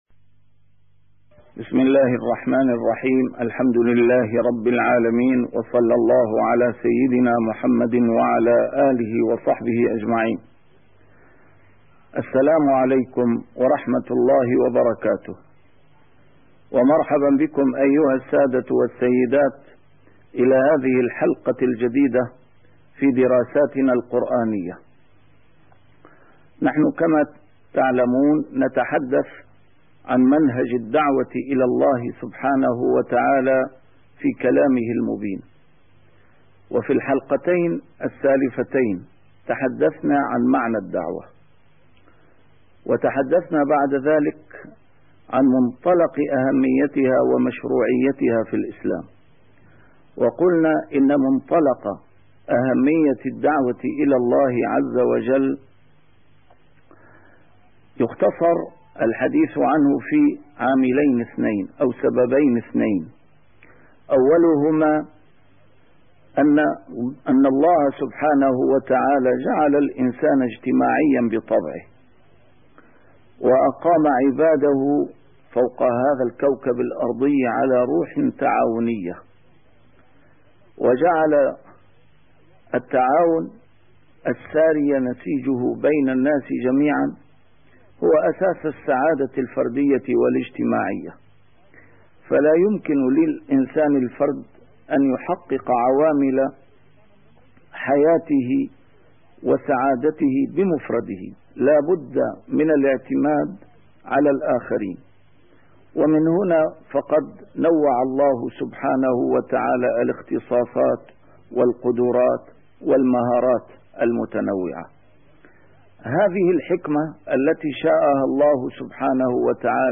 A MARTYR SCHOLAR: IMAM MUHAMMAD SAEED RAMADAN AL-BOUTI - الدروس العلمية - دراسات قرآنية - منهج الدعوة إلى الله سبحانه وتعالى في القرآن